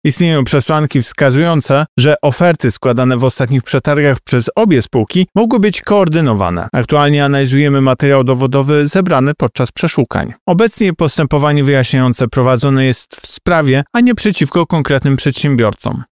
-podkreśla Tomasz Chróstny, Prezes UOKiK